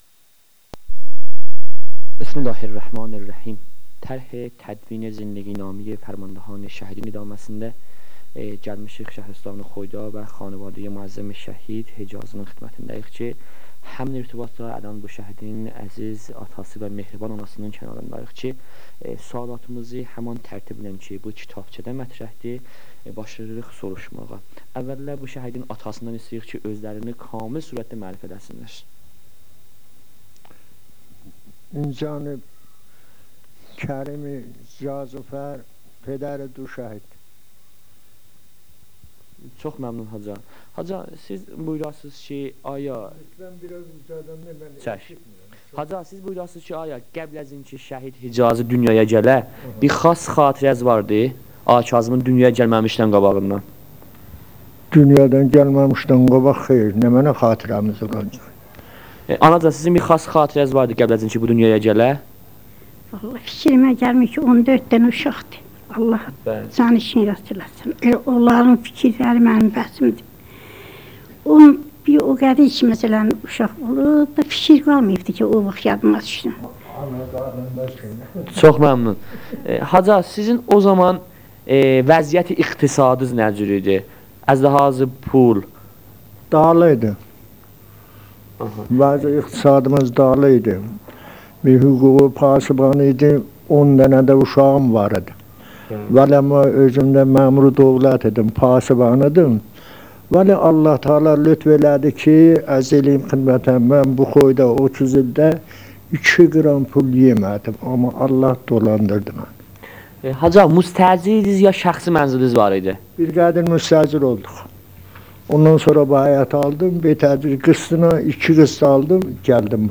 مصاحبه صوتی